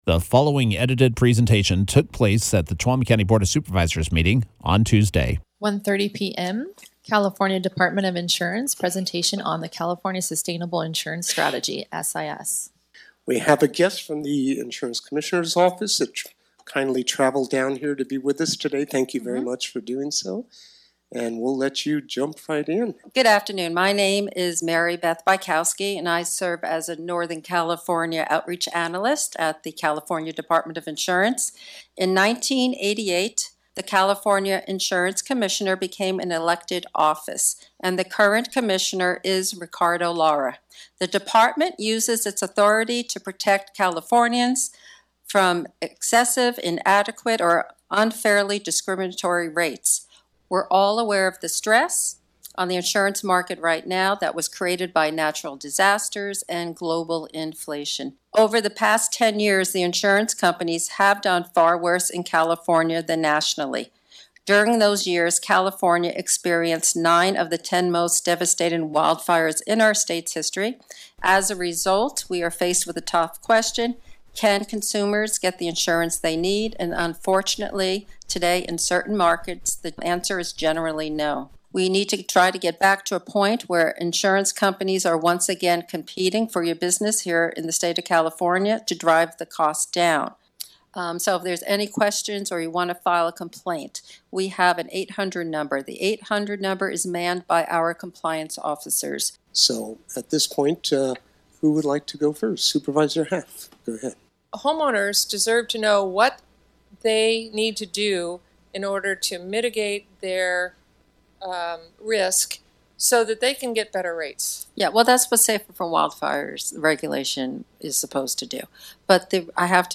The California Department of Insurance gave a Presentation on the California Sustainable Insurance Strategy (SIS) during the Tuolumne County Board of Supervisors meeting on Tuesday.
Here are the edited remarks: